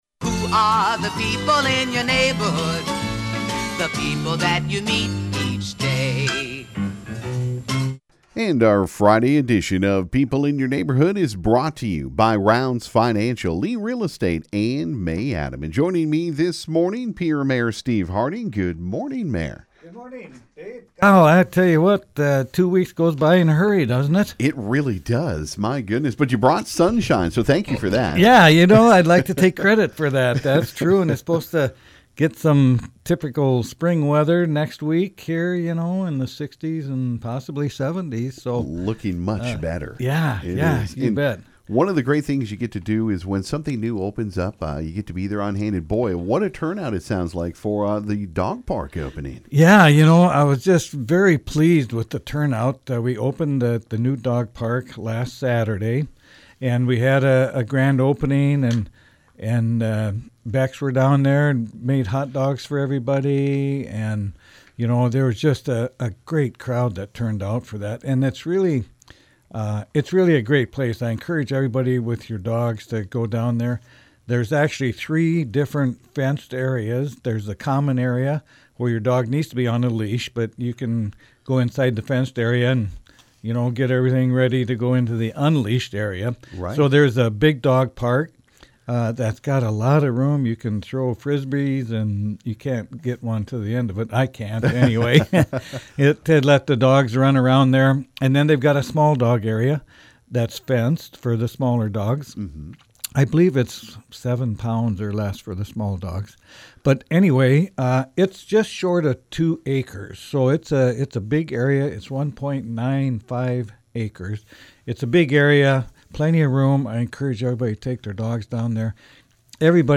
On this Friday Pierre Mayor Steve Harding stopped by KGFX for today’s People In Your Neighborhood. He talked about the new Dog Park and the Grand Opening last Saturday. He also talked about Arbor Day and that Pierre received the Tree City USA designation for the 40th time.